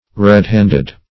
Red-hand \Red"-hand`\ (r?d"h?nd`), Red-handed \Red"-hand`ed\